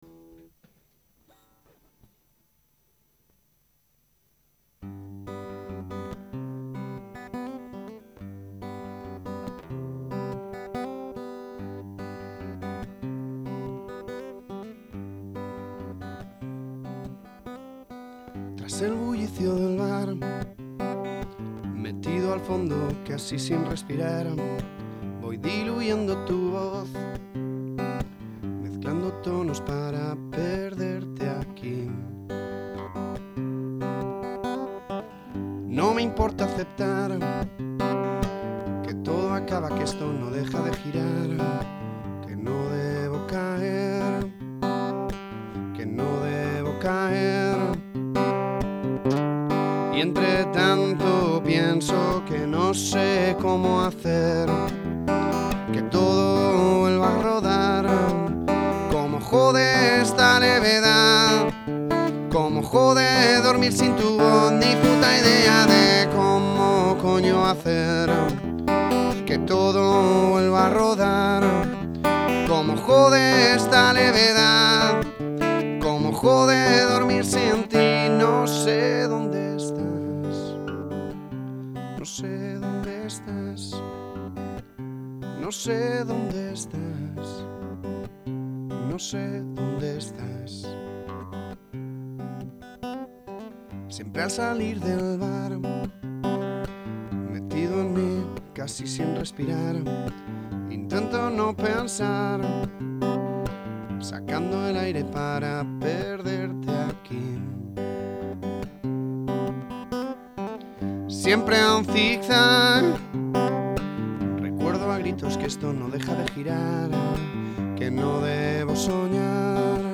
Si resumimos la grabación fue poner en marcha el ordenador, encender la mesa, los colegas dando vueltas, las colegas persistentemente vestidas, yo tocando ahí en medio, sin voz, con mucha cerveza, demasiado tabaco, una canción tras otra sin parar el ordenador, ronco, cansado, con un huevo hinchado, persistentemente vestidas, ya te digo… bueno, así fue.